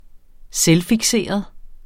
selvfikseret adjektiv Bøjning -, ..fikserede Udtale [ -figˌseˀʌð ] Betydninger (alt) for optaget af sig selv, sine egne behov og interesser, sin virkning på andre mennesker, osv.